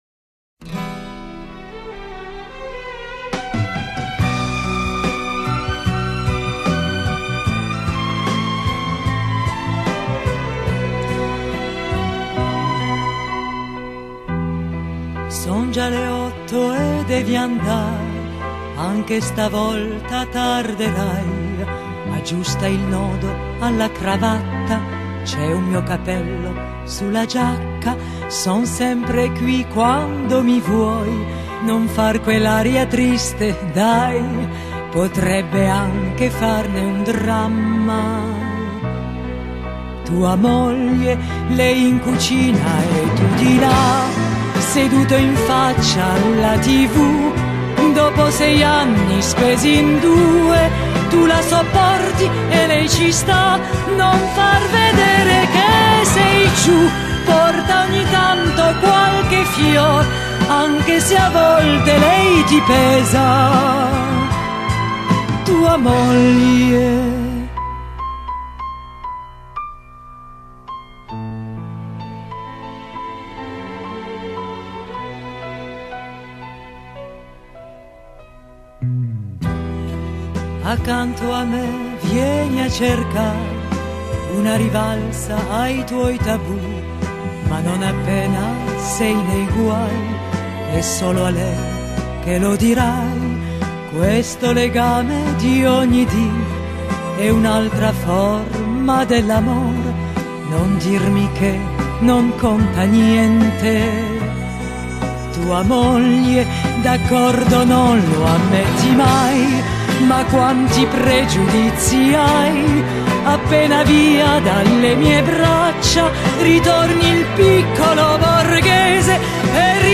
ترانه ایتالیایی Canzone Italiana